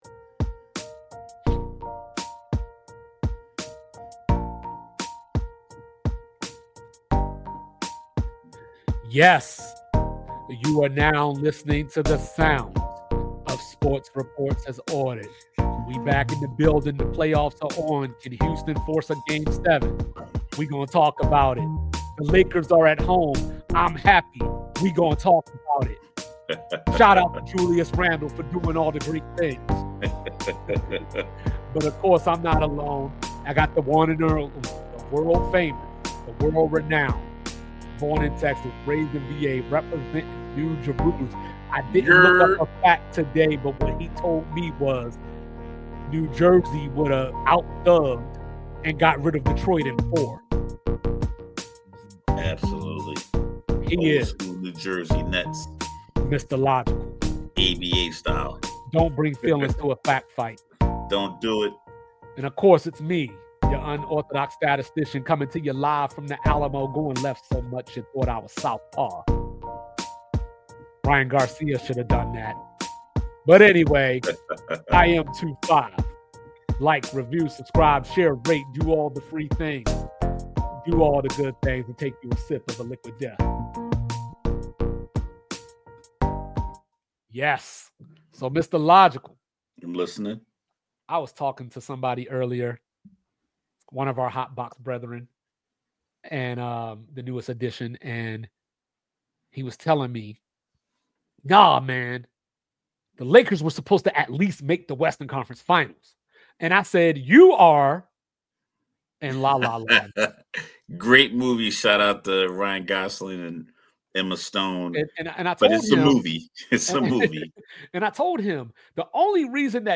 Rational thought-out analysis with friendly dust-ups.